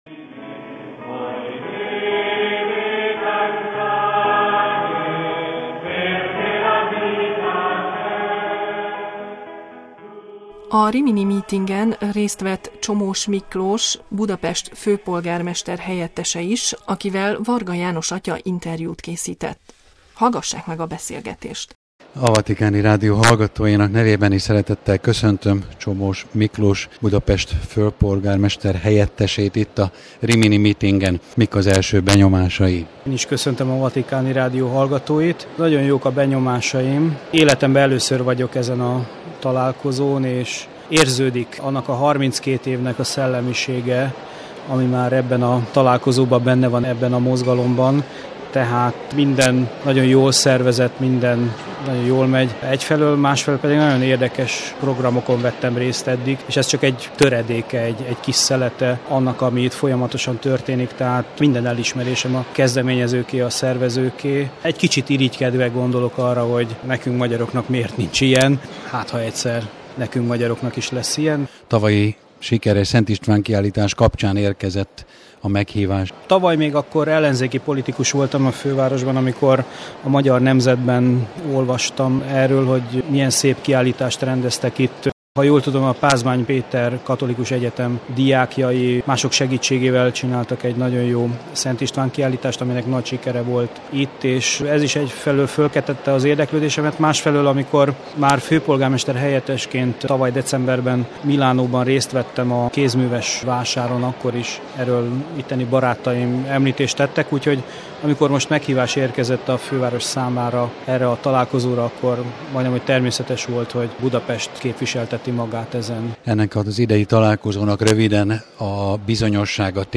„Remélem, egyszer nekünk, magyaroknak is lesz egy ilyen rendezvényünk” – interjú Csomós Miklóssal, Budapest főpolgármester-helyettesével a Rimini Meetingen